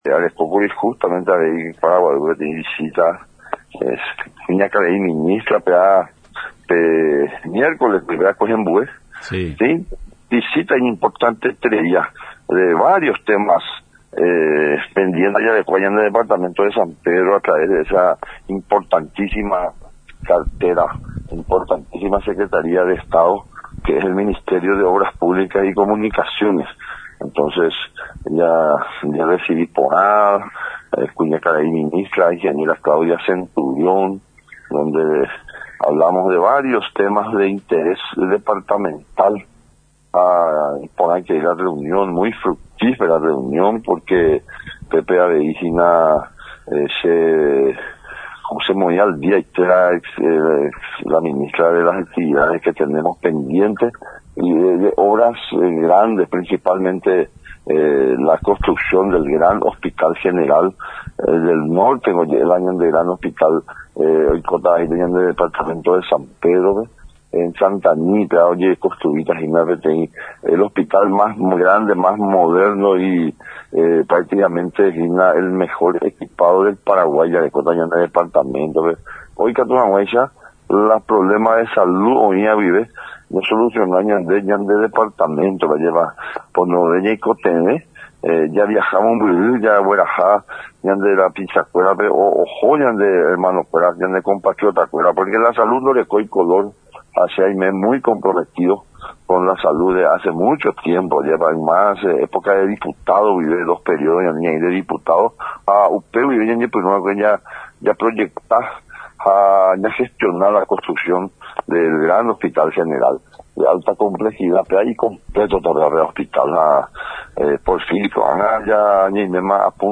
EDITADO-8-FREDDY-DECCLESIIS-GOBERNADOR.mp3